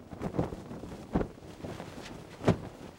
cloth_sail10.R.wav